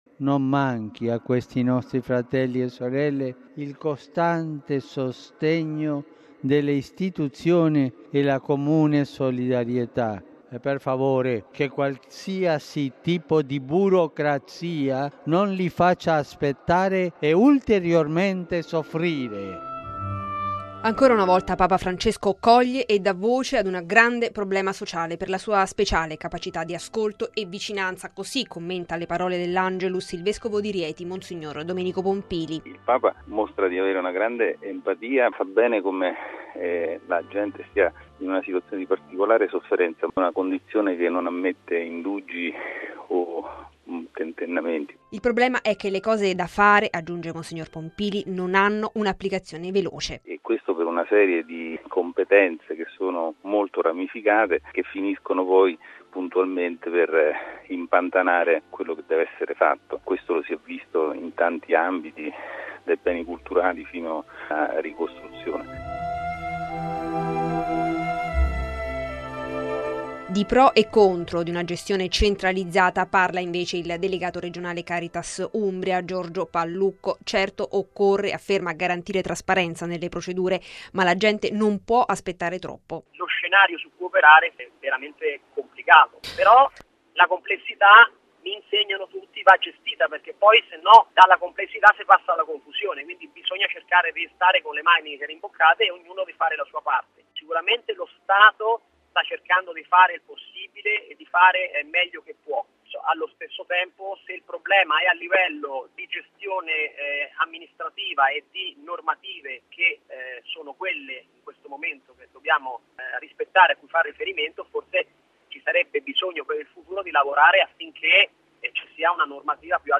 Il Papa ieri ha sollevato la questione all’Angelus lanciando un forte appello.